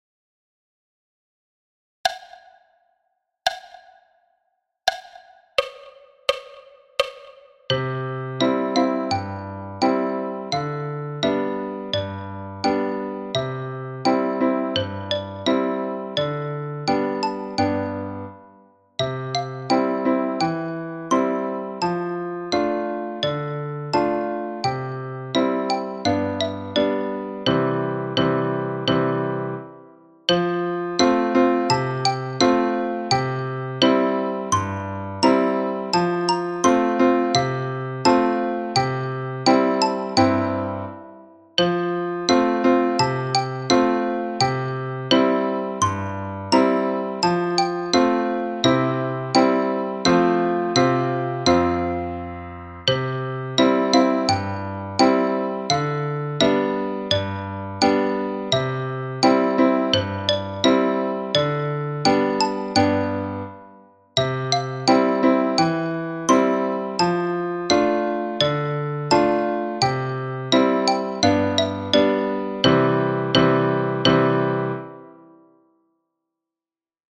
Danse du canard – tutti à 85 bpm